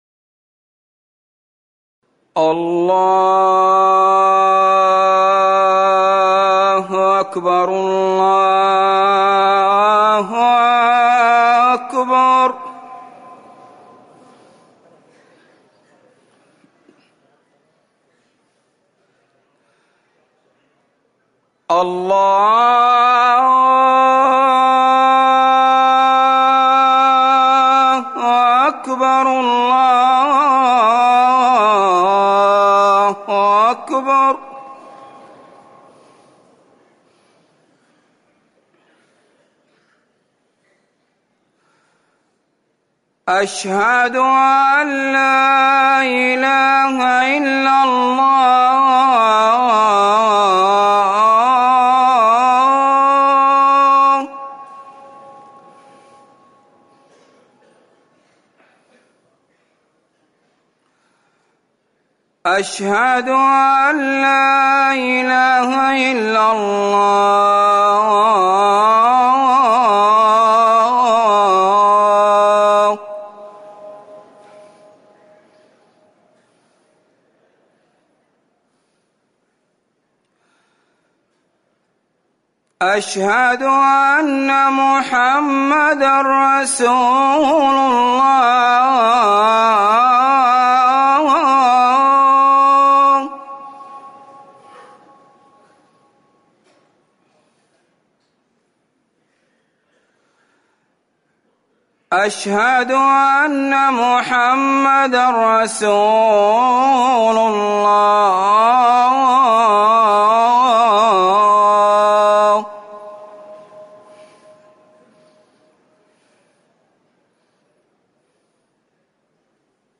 أذان العصر
تاريخ النشر ٢١ صفر ١٤٤١ هـ المكان: المسجد النبوي الشيخ